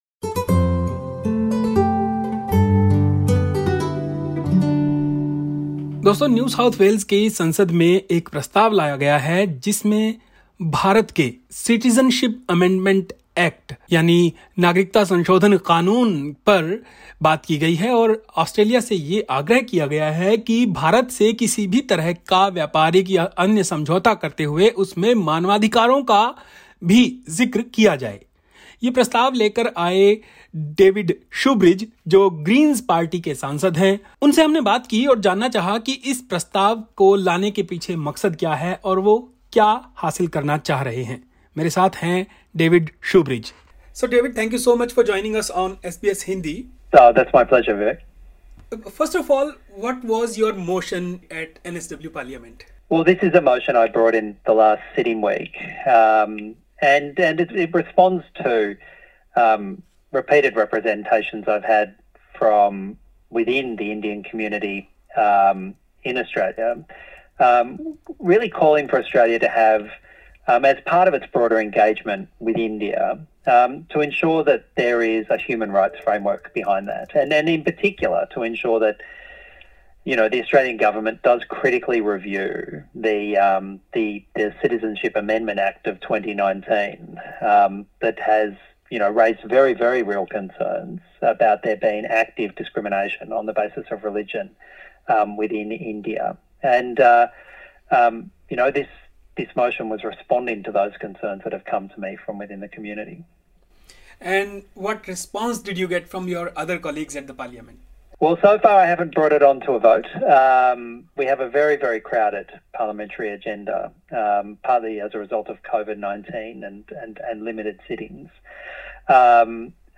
Listen to the interview with David Shoebridge: